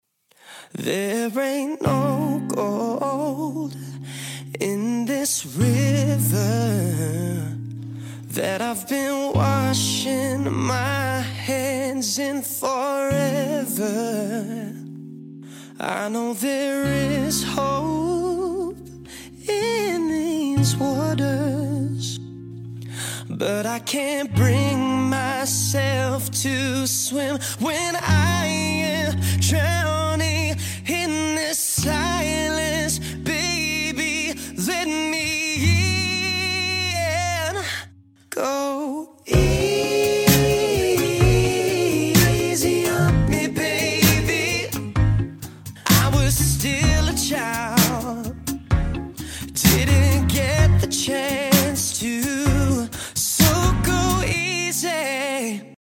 Guitar | Vocals | Looping